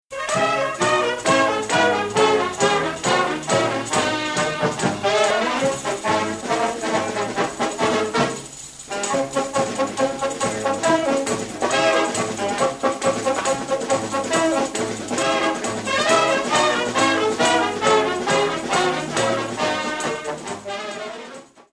Gattung: Samba
Besetzung: Blasorchester